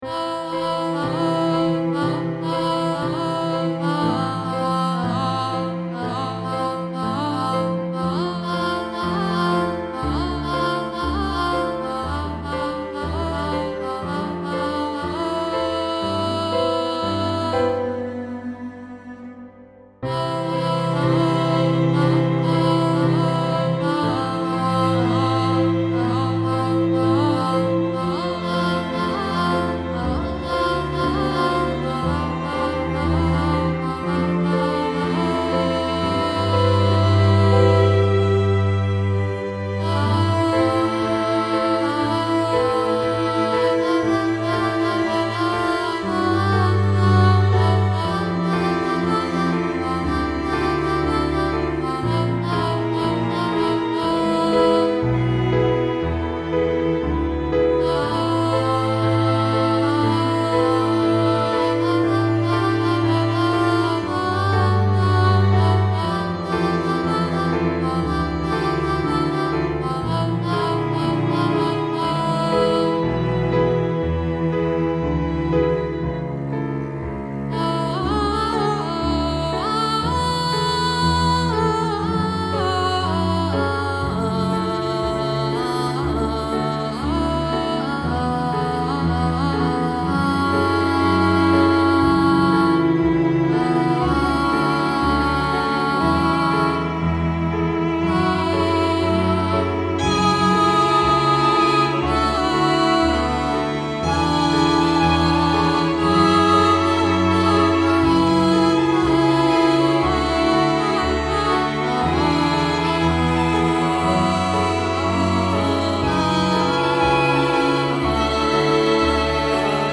Unfinished Pop chamber track for my sister
As in title i m working on chamber pop track for my little sister ^^ everything is done in logic pro x so no music sheet so far. 2 different sections that i ll try to fix later in a better pop form. a synth is playing the voice and vst the other instruments( bass cello v1 v2 alto)